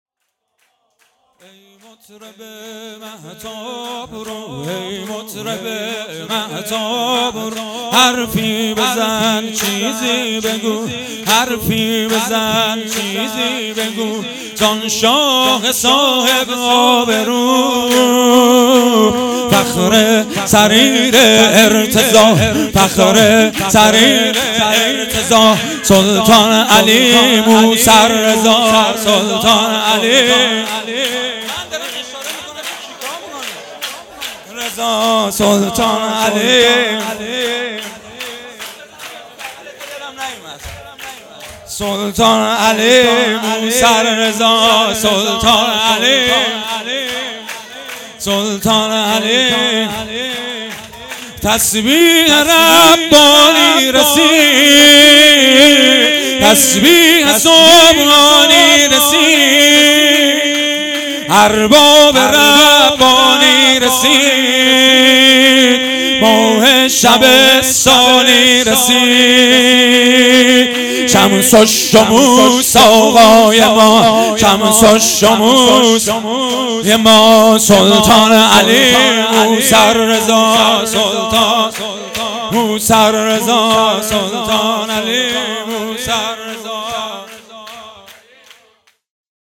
شعرخوانی
جشن ولادت امام رضا علیه السلام